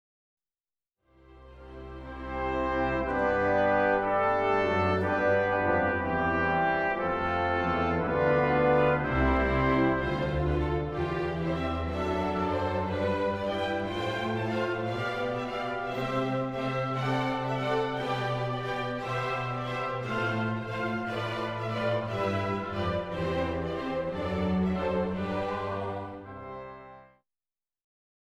Chorale
a prelude setting